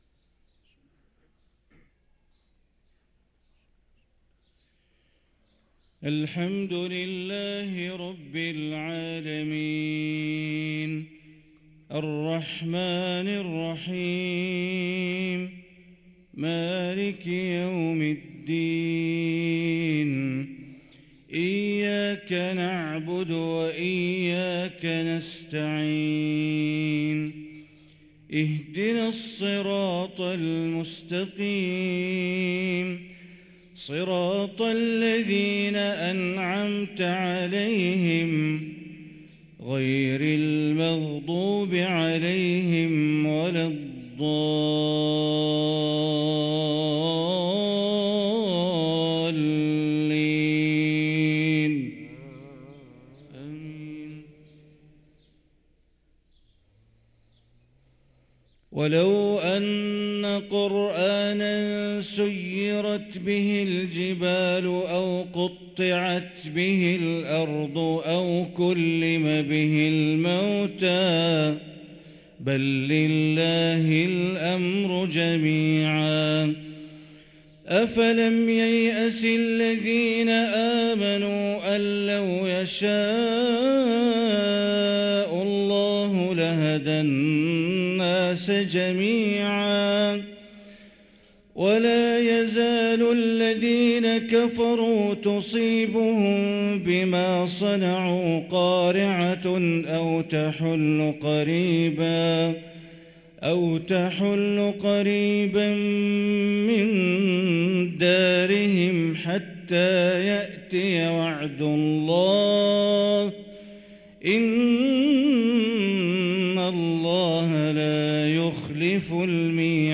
صلاة الفجر للقارئ بندر بليلة 3 جمادي الآخر 1445 هـ